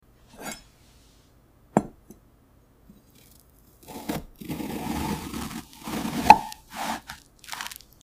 What is it ? ASMR sound effects free download